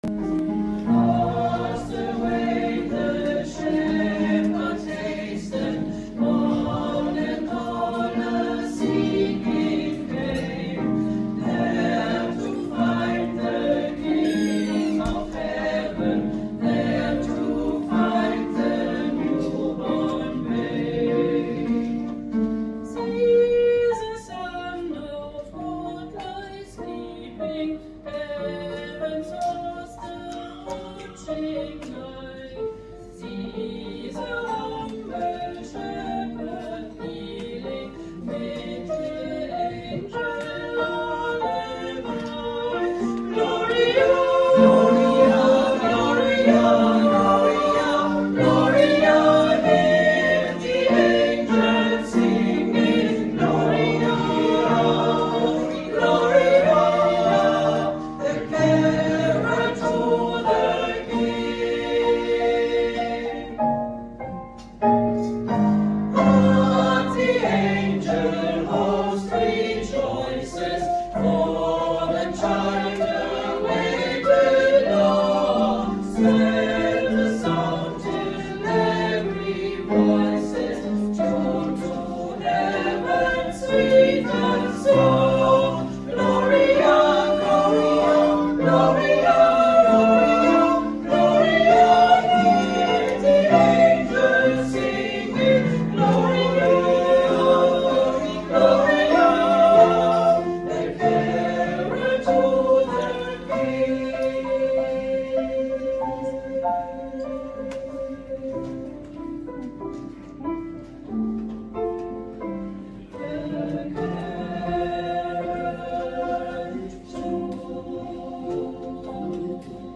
Ein Chor tritt auf der Bühne mit einem Pianisten und einem Dirigenten neben einem großen Weihnachtsbaum auf, während das Publikum an Tischen im Vordergrund sitzt.
Musikalisch wurden die Gäste von den Grevener Tontauben begleitet, die mit festlichen wie fröhlichen Liedern ein Repertoire boten, das selbst notorische Weihnachtsmuffel zum Mitsummen brachte.
Grevener_Tontauben_Lang.mp3